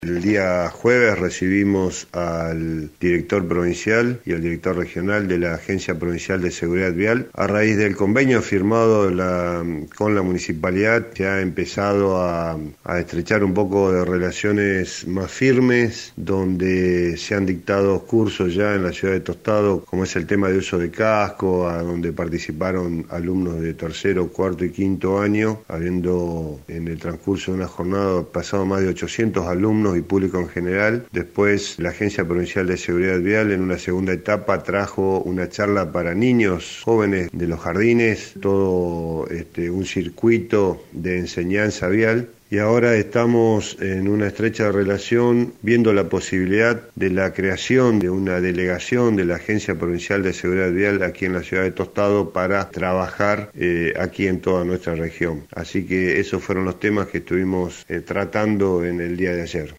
El propio intendente Enrique Mualem dio más detalles: